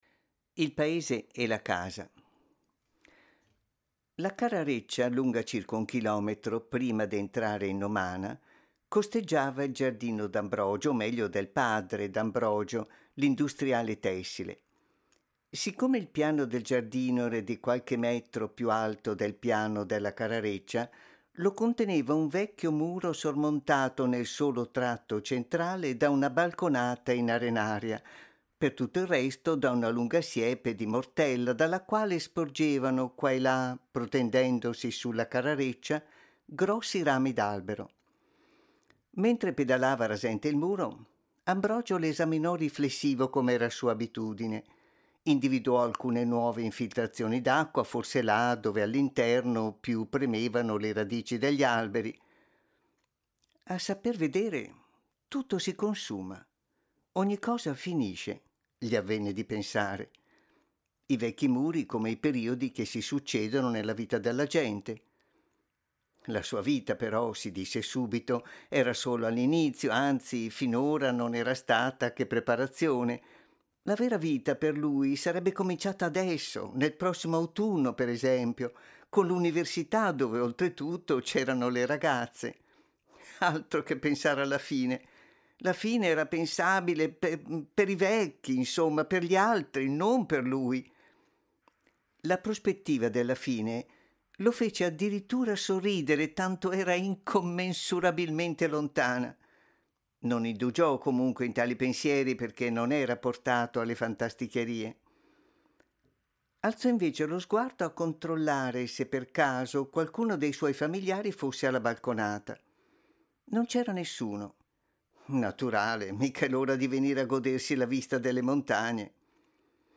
Proponiamo la seconda delle letture tratte dalle opere di Eugenio Corti che i visitatori delle giornate di primavera del FAI hanno potuto ascoltare il 25 e il 26 marzo scorsi in occasione della visita a villa Corti, tratta da Il cavallo rosso.